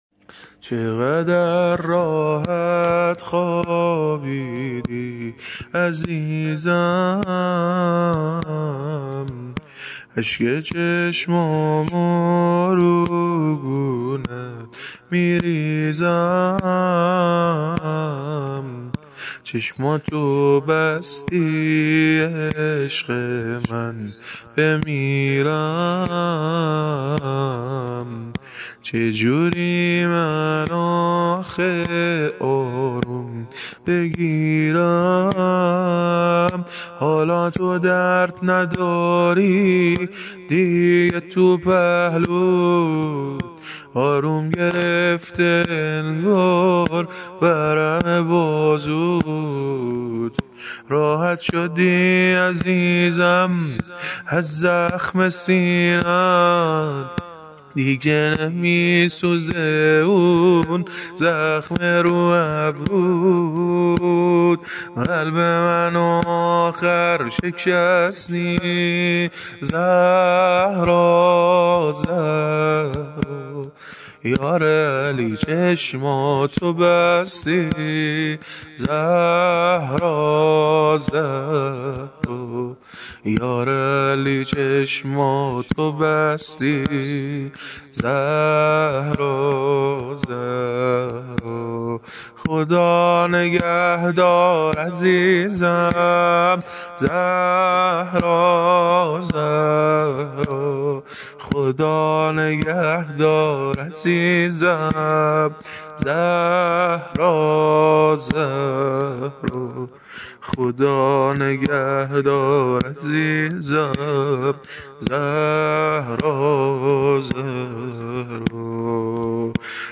شام غریبان ، شهادت